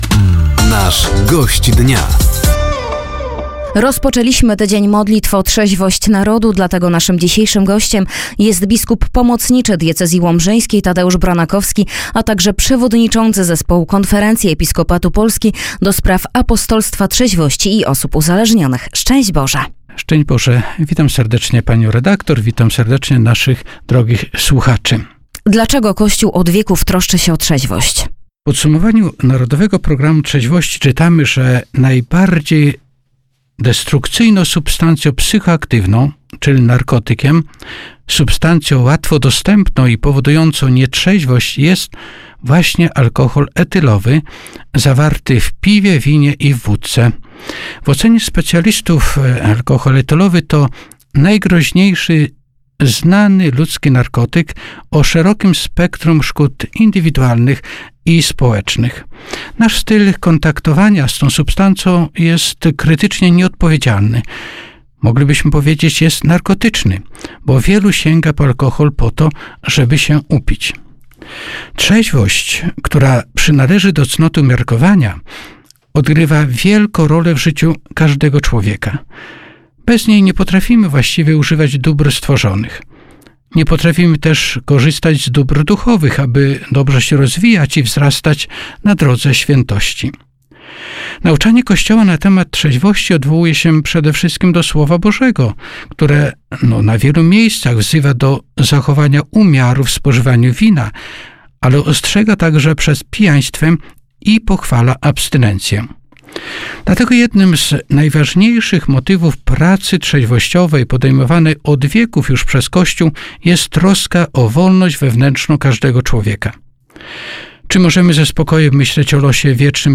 Gościem Dnia Radia Nadzieja był biskup pomocniczy diecezji łomżyńskiej oraz przewodniczący Zespołu Konferencji Episkopatu Polski ds. Apostolstwa Trzeźwości i Osób Uzależnionych – ks. bp Tadeusz Bronakowski. Tematem rozmowy był trwający 57. Tydzień Modlitw o Trzeźwość Narodu.